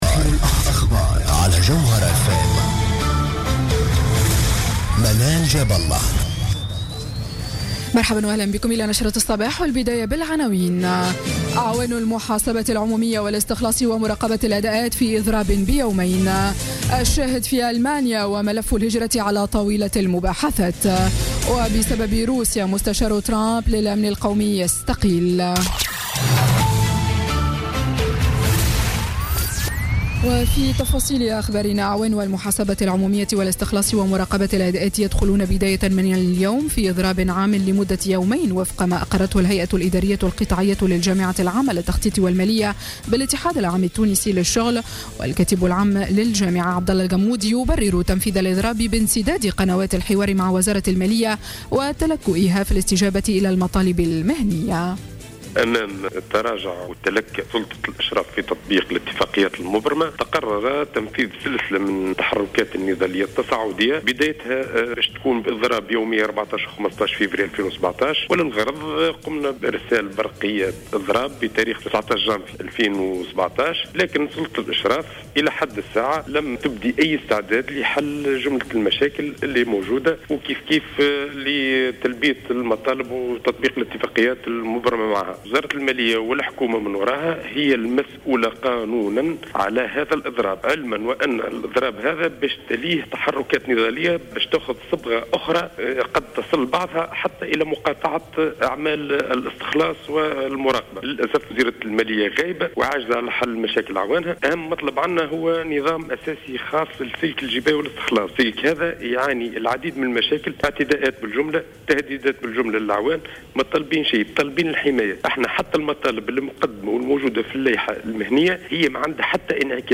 نشرة أخبار السابعة صباحا ليوم الثلاثاء 14 فيفري 2017